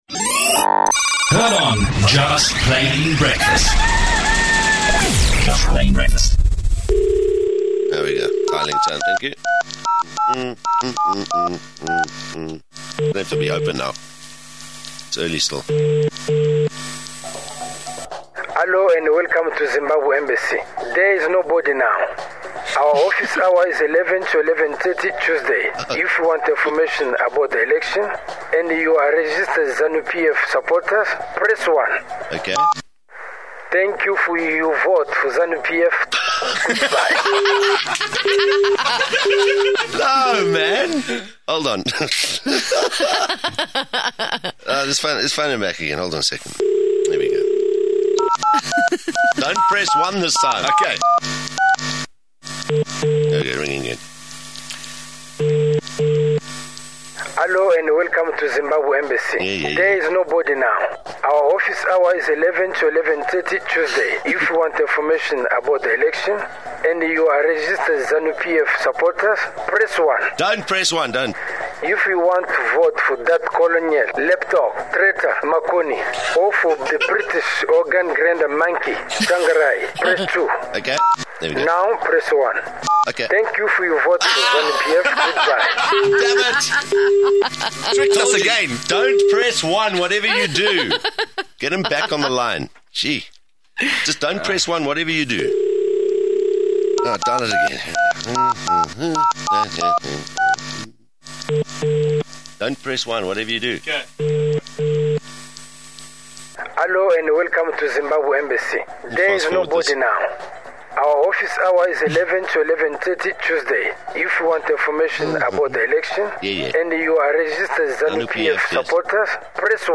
• Call the Zimbabwe Embassy - Audio spoof
Listen to this recording of a call to the Zimbabwean Embassy and learn about the candidates in the 29 March election, and Zanu PF's plans to rig the election.